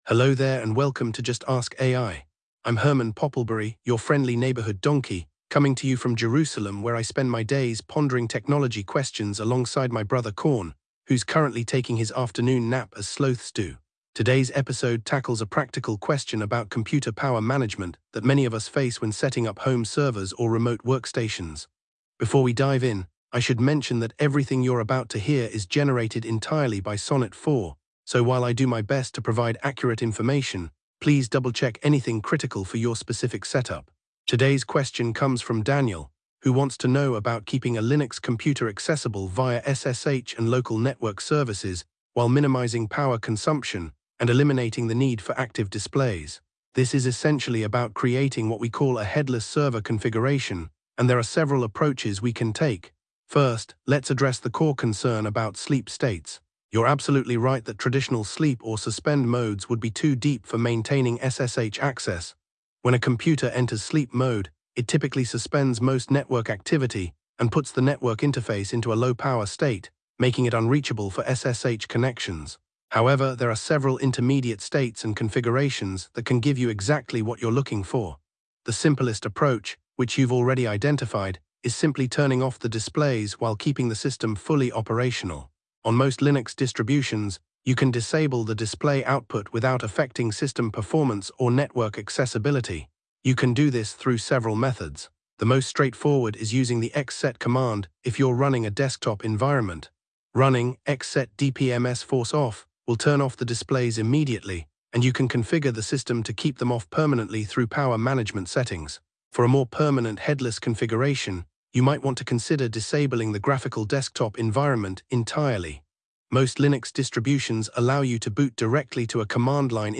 AI-Generated Content: This podcast is created using AI personas.
This episode was generated with AI assistance. Hosts Herman and Corn are AI personalities.